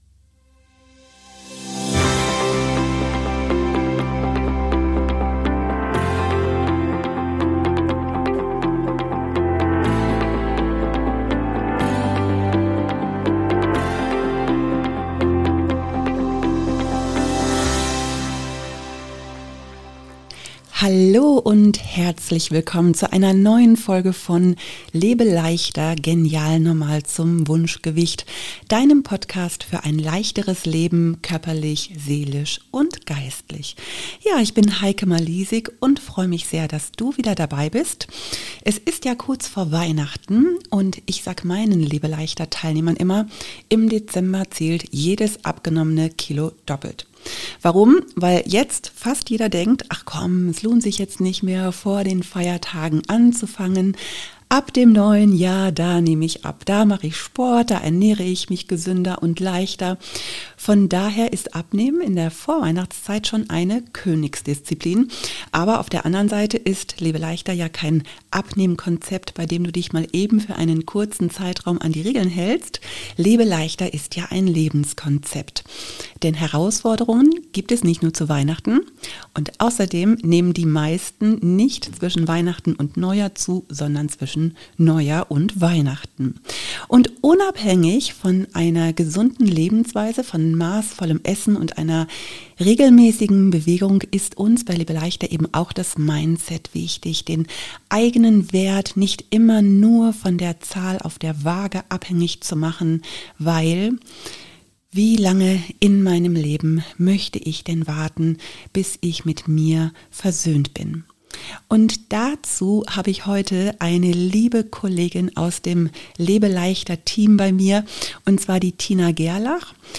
Ein ehrliches, ermutigendes Gespräch über Mindset, Dauergrasen, Stress, Selbstannahme, das Lebe leichter 6×1 als hilfreiches Geländer im Alltag – und darüber, warum Abnehmen leichter wird, wenn wir aufhören, gegen uns selbst zu kämpfen.